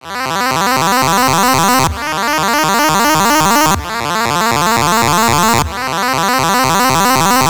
End Credits C 128.wav